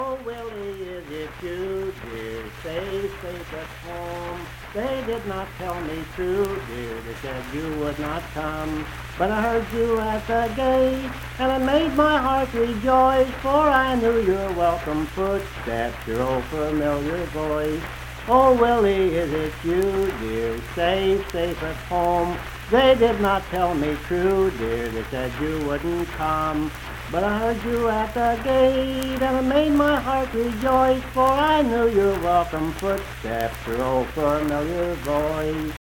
Unaccompanied vocal music
Performed in Hundred, Wetzel County, WV.
Voice (sung)